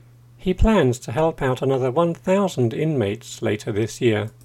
DICTATION 4